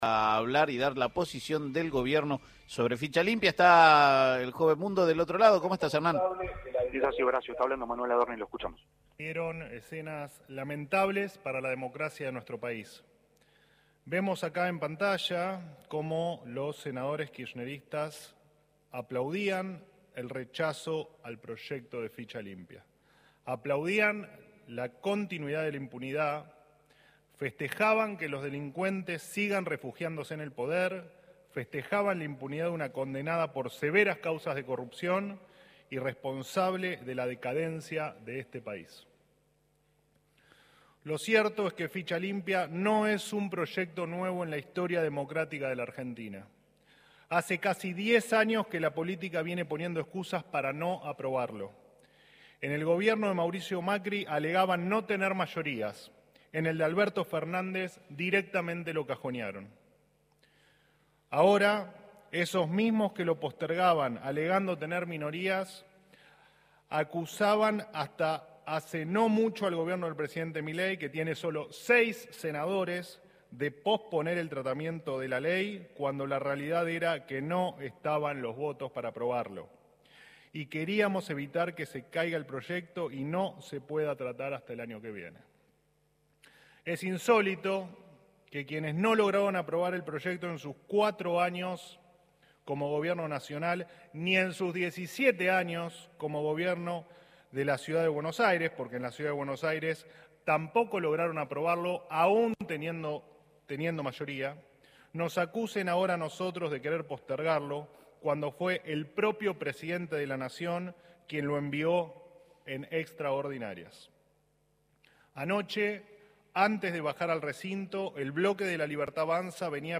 CONFERENCIA DE PRENSA